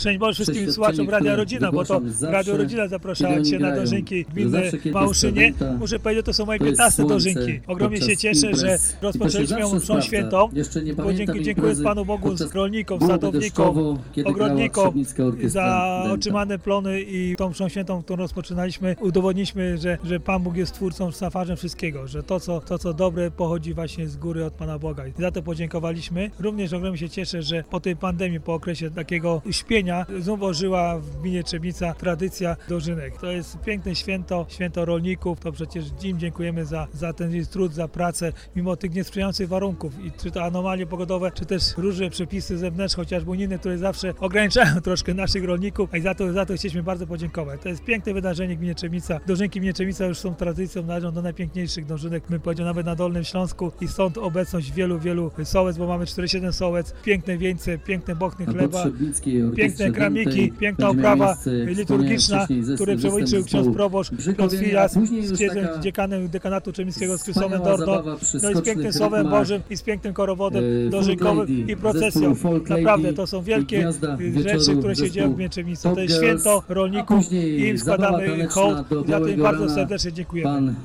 Burmistrz Marek Długozima dziękował rolnikom, sadownikom oraz ogrodnikom i podkreślał wagę święta dla Gminy Trzebnica.